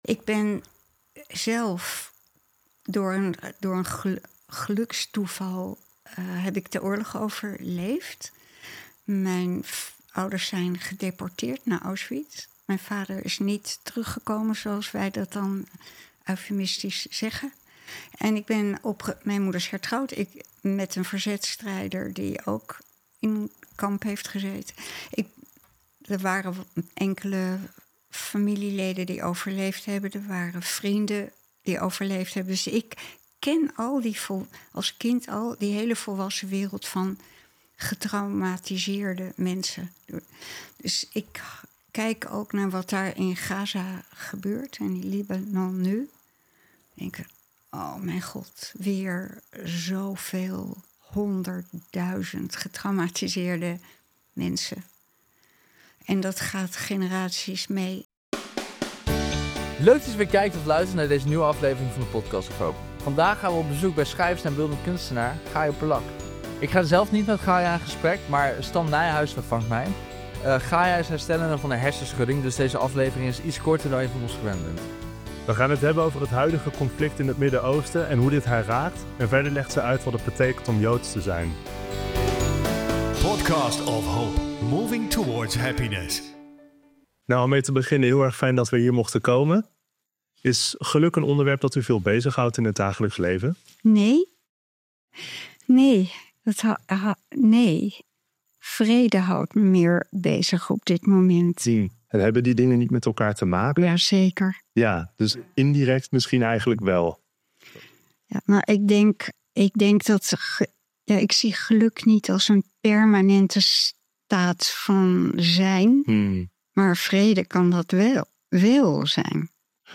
In de Podcast of HOPE komen interessante gasten aan het woord